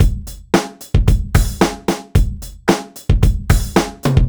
Track 15 - Drum Break 02.wav